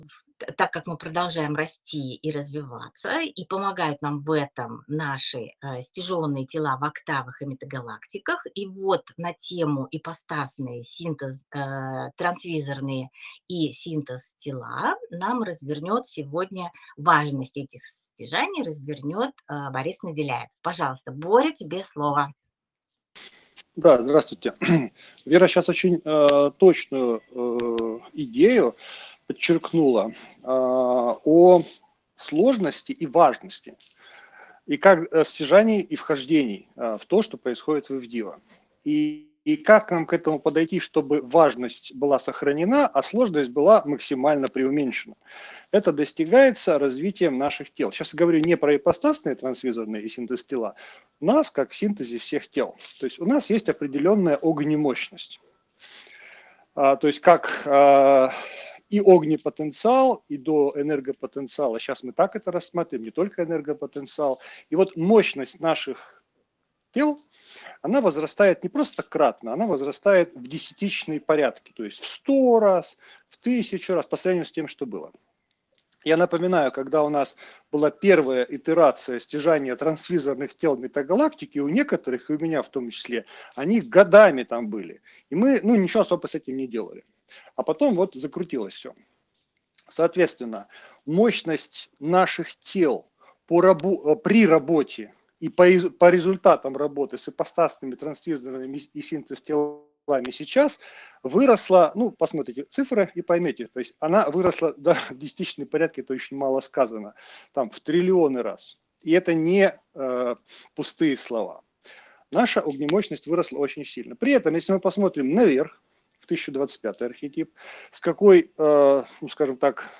Доклад на планёрке подразделения ИВДИВО Москва, Россия 6 декабря 2023 г.